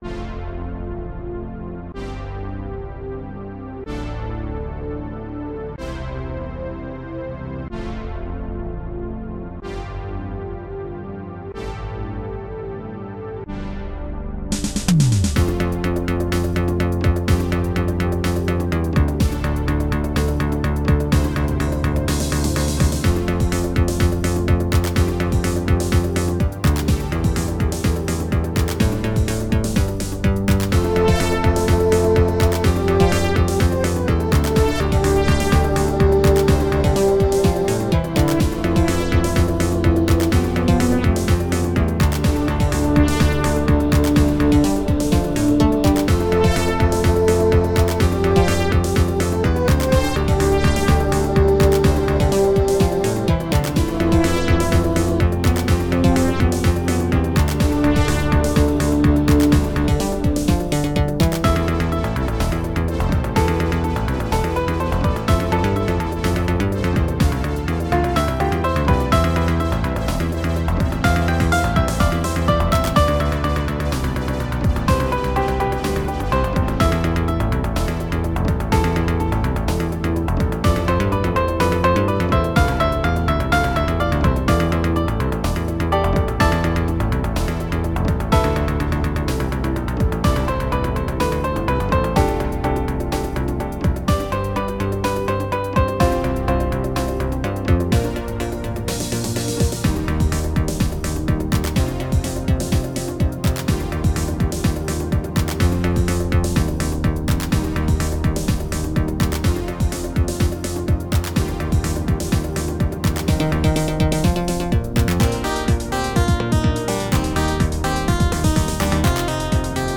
The Simplest Synthwave
very simple but also quite catchy